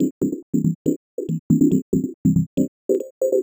tx_synth_140_resdegra_CMaj.wav